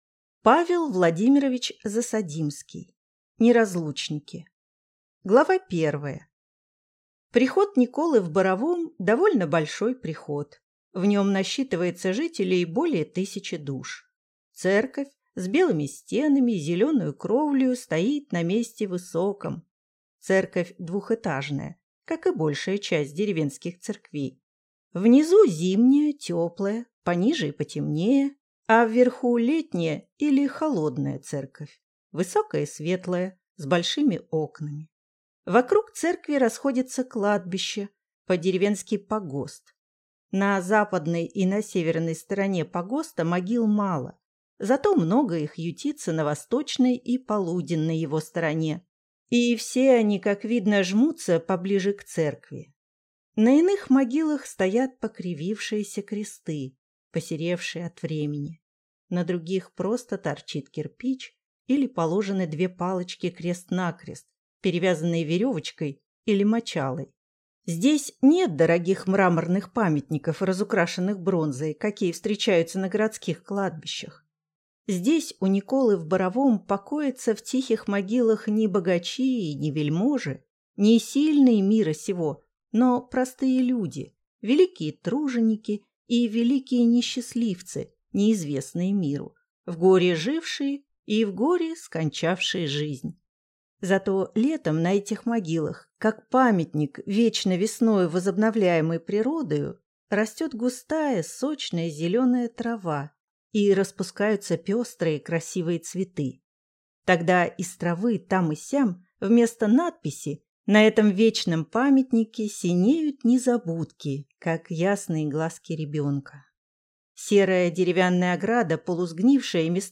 Аудиокнига Неразлучники | Библиотека аудиокниг